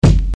Kicks
nt kick 4.wav